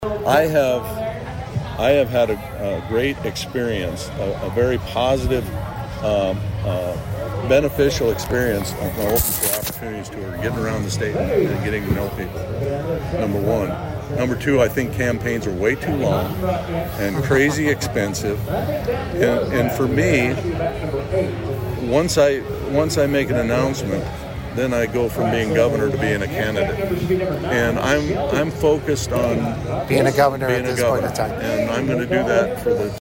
Prior to that Governor Rhoden did an interview with HubCityRadio to address a variety of topics.